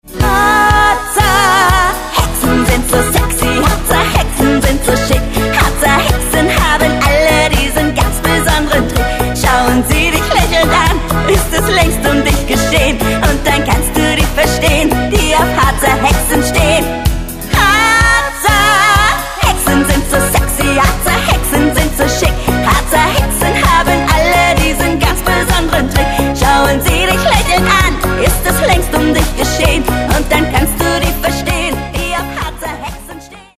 Gesang, (E-Bass, Gitarre
Schlagzeug
Percussion